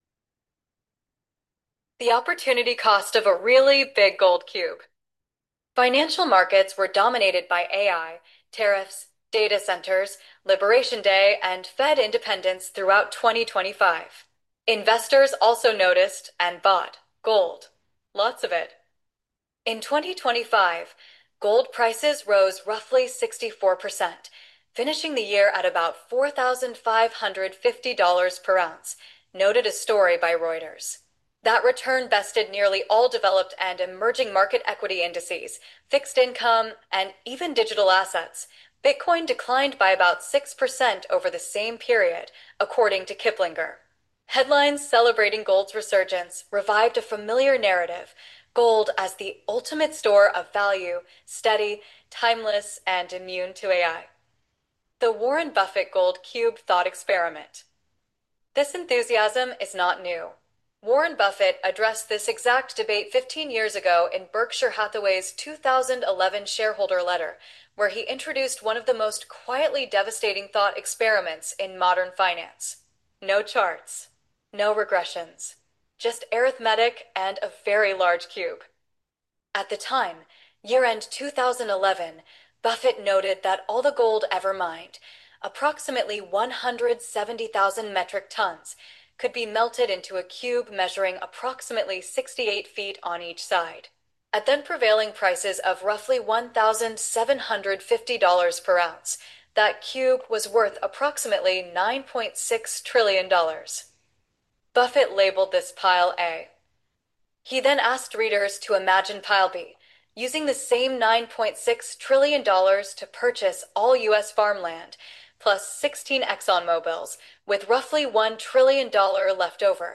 Two Callan investing experts provide an analysis and update of the Warren Buffett gold cube investing lesson that he discussed in his 2011 shareholder letter.